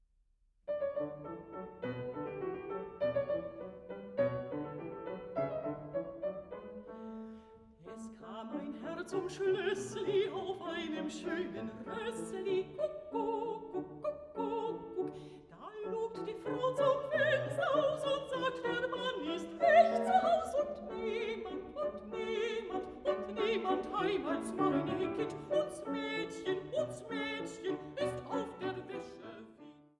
Mezzosopran
Klavier